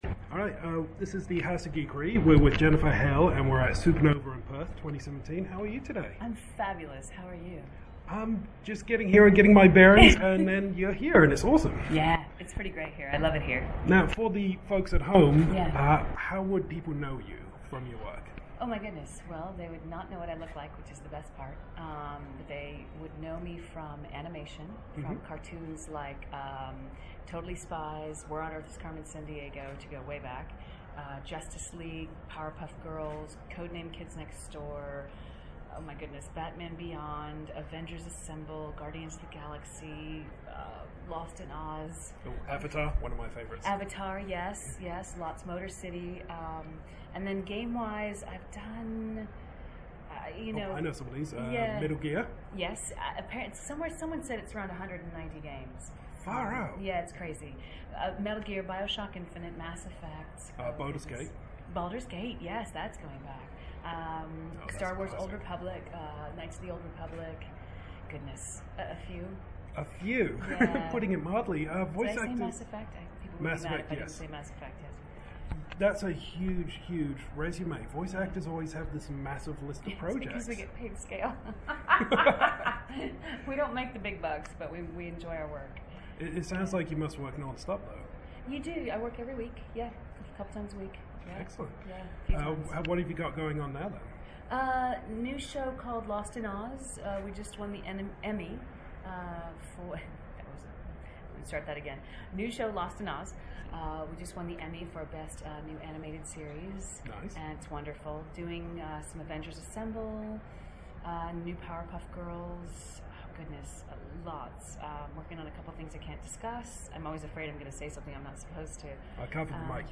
Exclusive Interview with Jennifer Hale